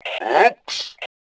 gbl.oops.WAV